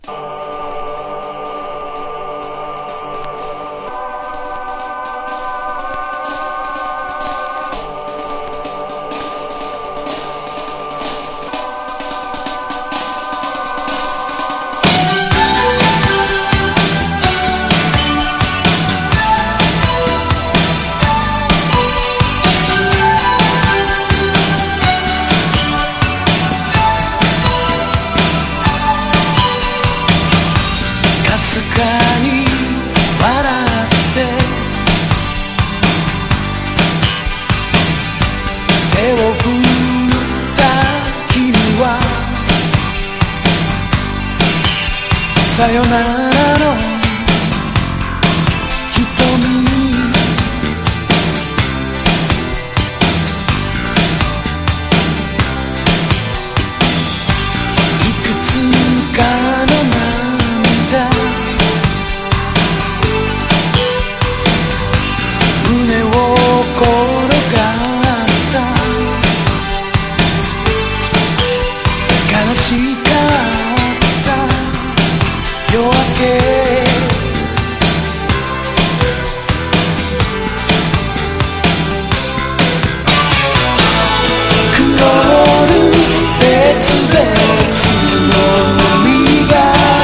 CITY POP / AOR